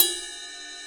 RS RIDE 2.wav